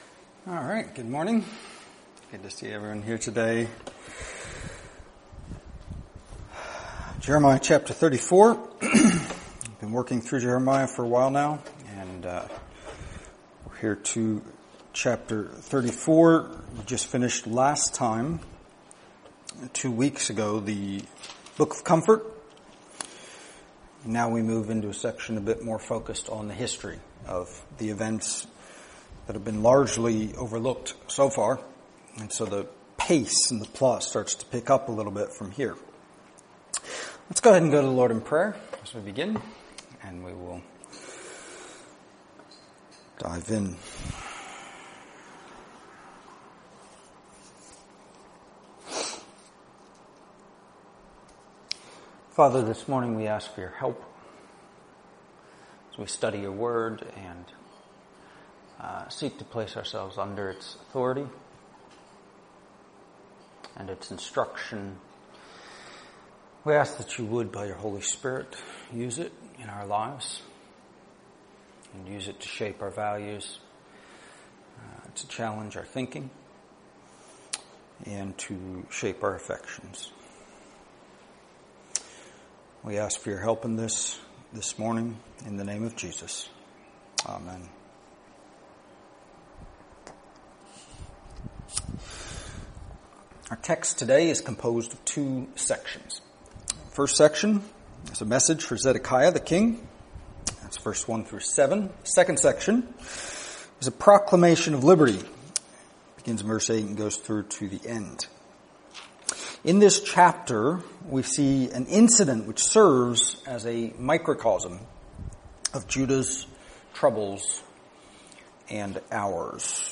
Download mp3 Previous Sermon of This Series Next Sermon of This Series Coming Soon